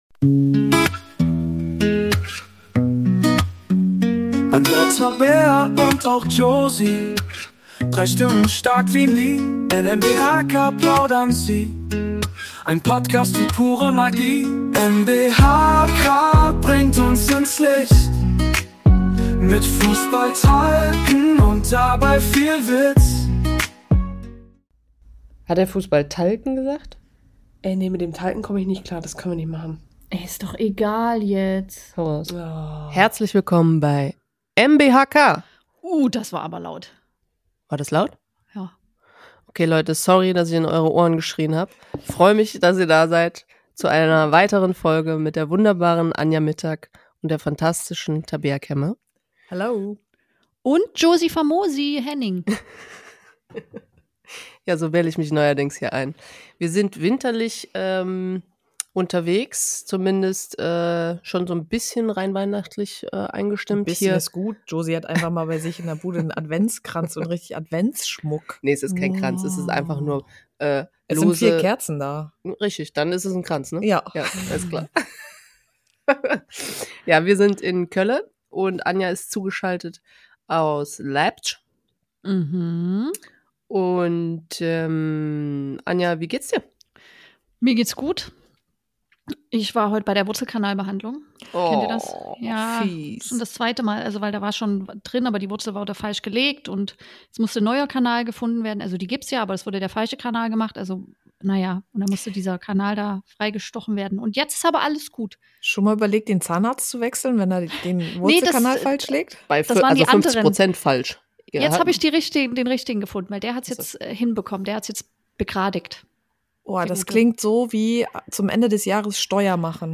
Die drei ehemaligen Fußball Nationalspielerinnen Anja Mittag, Josephine Henning und Tabea Kemme nehmen euch mit in ihre Welt. Sie berichten von eigenen Erfahrungen und plaudern hier und da ein bisschen zu viel aus dem Nähkästchen. Die beiden bringen für euch Gäste aus dem Profisport an den Mittagstisch, um ehrlich und offen Sinnfreies zu bequatschen und Tiefgründiges zu ignorieren - oder manchmal auch andersrum.